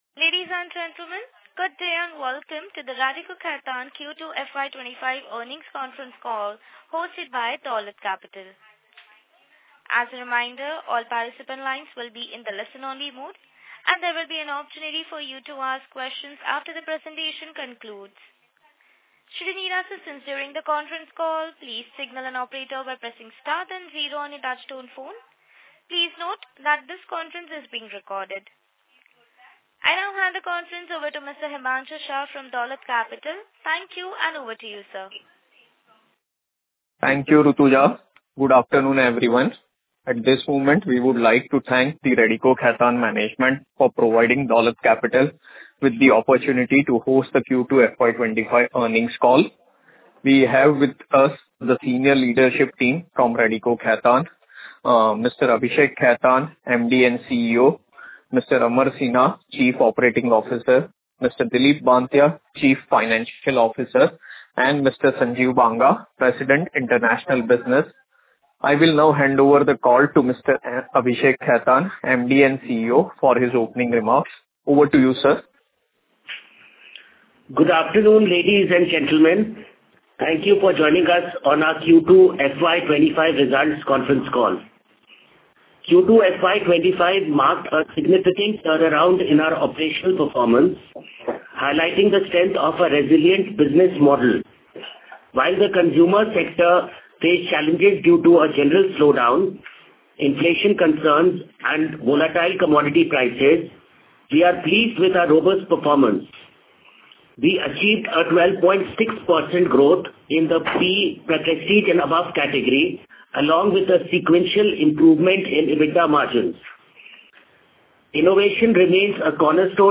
Concalls